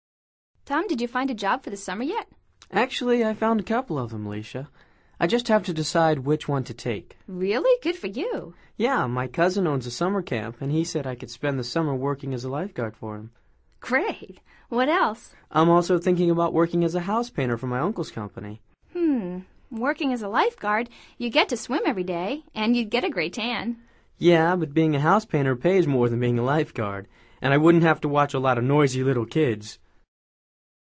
Escucha atentamente esta conversación entre Alicia y Tom y selecciona la respuesta más adecuada de acuerdo con tu comprensión auditiva.